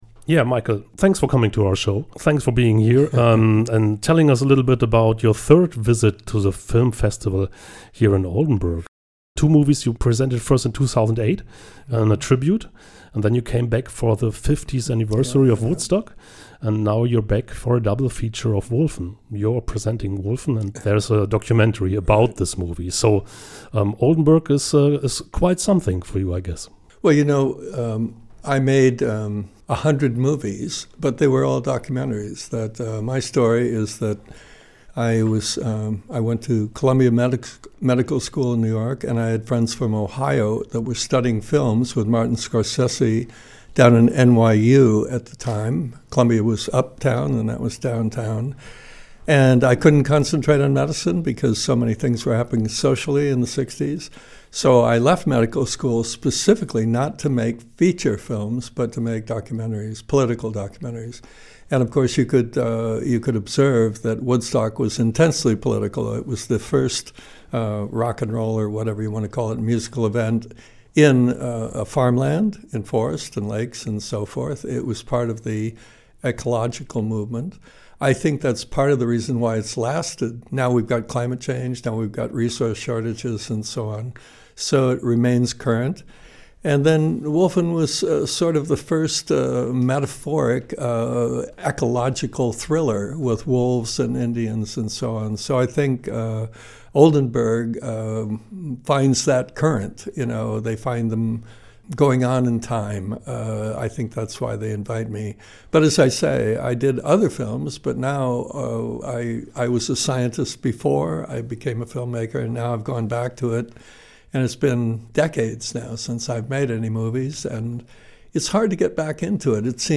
Radiointerview mit Michael Wadleigh im Studio von Oldenburg Eins – 13.09.2025